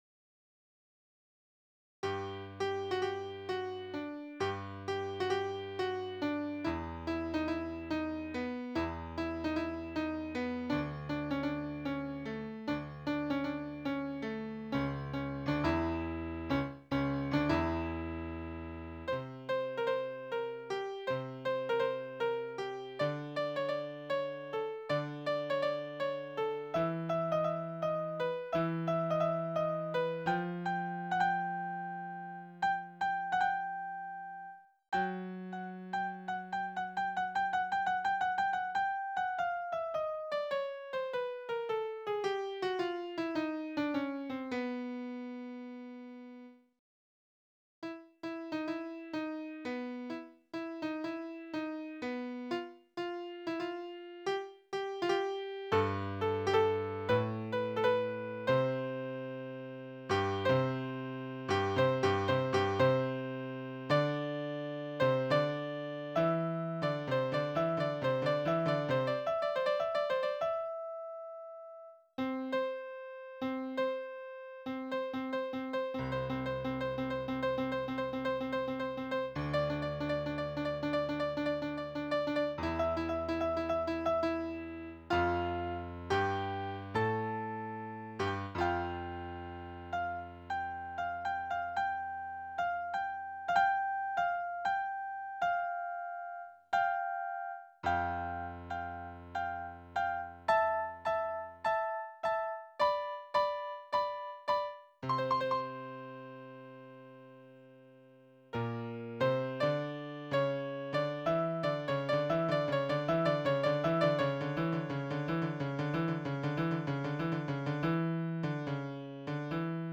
Pianista
Improwizacja jest całkowicie inna na początku, w środku i na końcu.